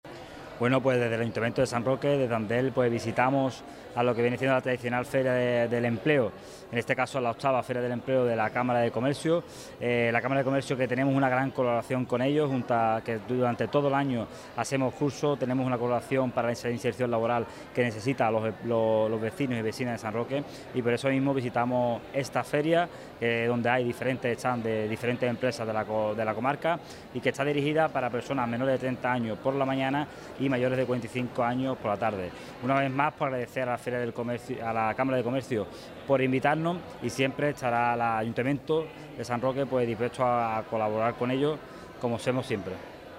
El concejal de empleo, Fernando Vega, ha asistido esta mañana a la inauguración de la Feria del Empleo, que pone en marcha por octavo año consecutivo, la Cámara de Comercio del Campo de Gibraltar.
VIII FERIA DEL EMPLEO CAMARA DE COMERCIO CAMPO DE GIBRALTAR TOTAL FERNANDO VEGA.mp3